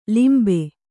♪ limbe